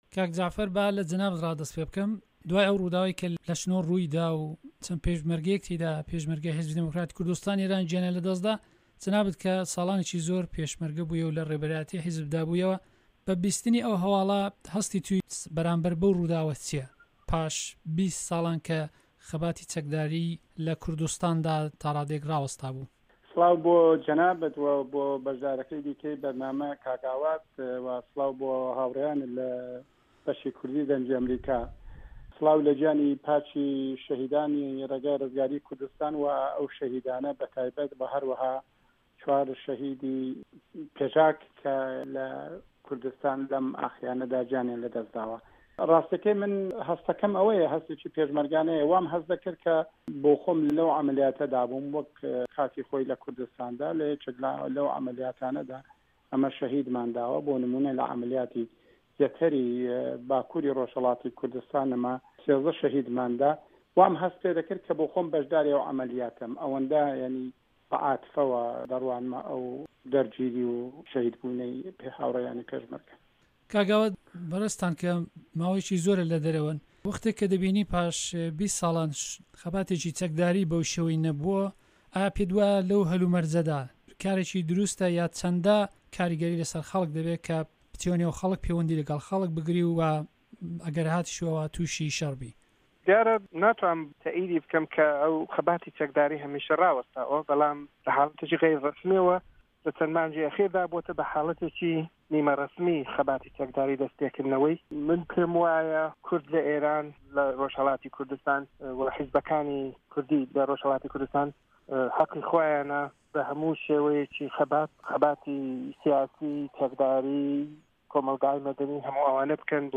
مێزگرد- کوردستانی ئێران